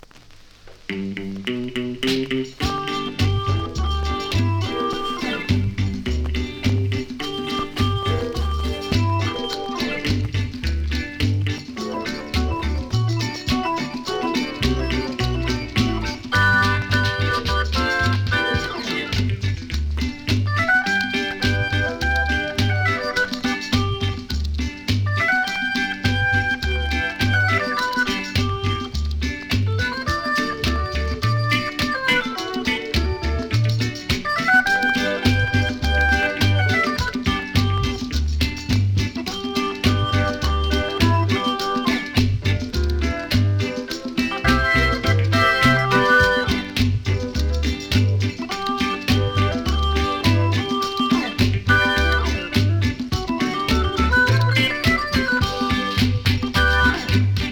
REGGAE 70'S
インスト・カバー